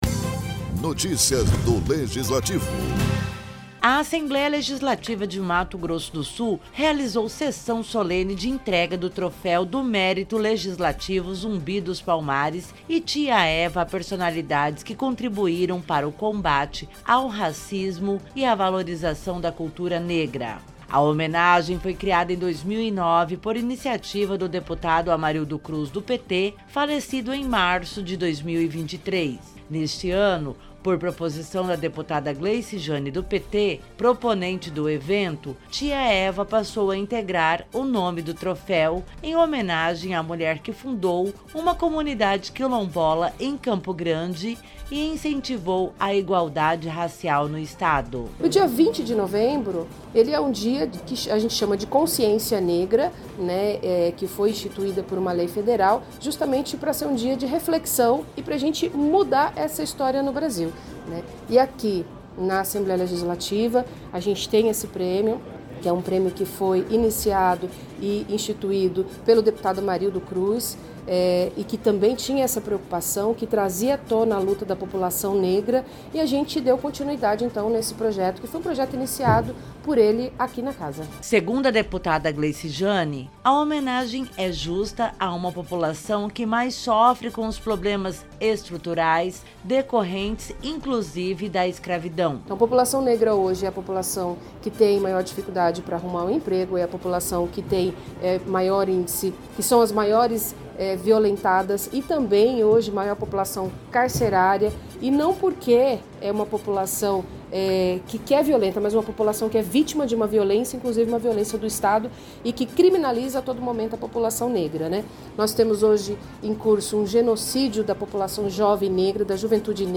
A Assembleia Legislativa de Mato Grosso do Sul (ALEMS) estava repleta de cores, sons e orgulho de quem lutou (e luta) por respeito e liberdade. A solenidade de entrega do Troféu do Mérito Legislativo Zumbi dos Palmares e Tia Eva foi entregue a 26 personalidades que contribuíram para o combate ao racismo e a valorização da cultura negra.